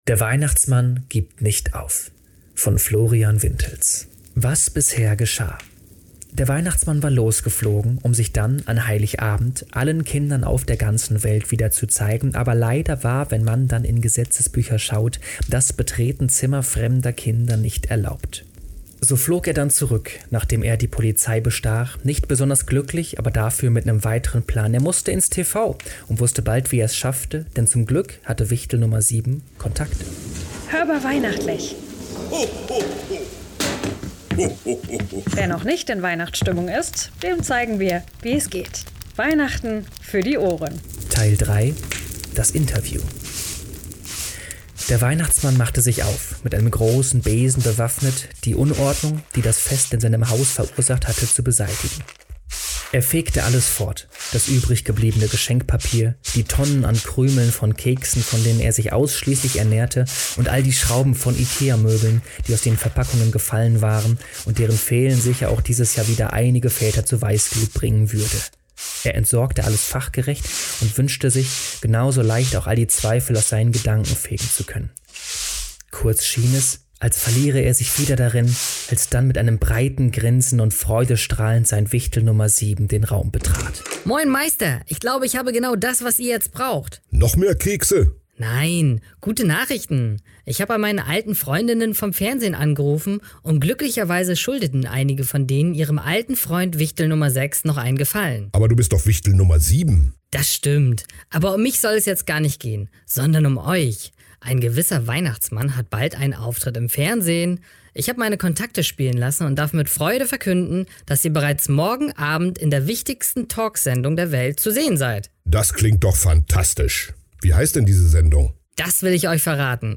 Text/Erzähler